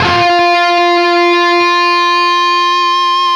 LEAD F 3 CUT.wav